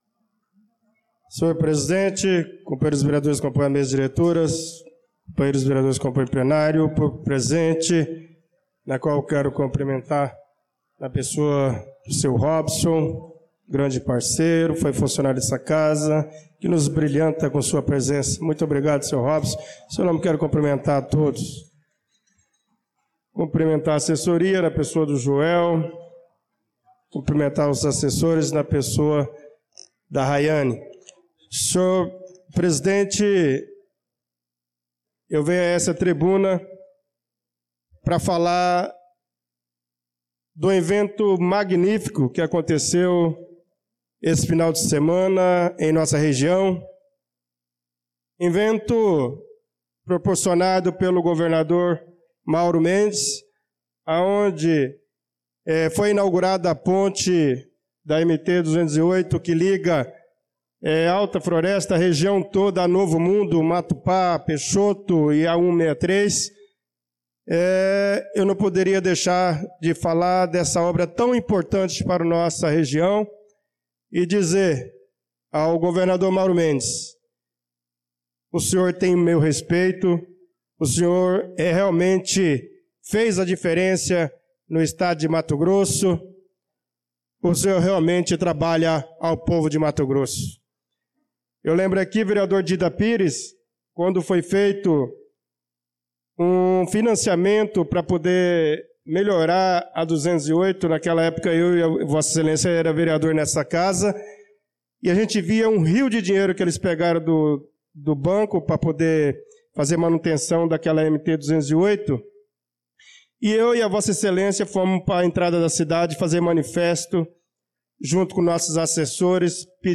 Pronunciamento do vereador Bernardo Patrício na Sessão Ordinária do dia 25/02/2025